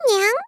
menuback.wav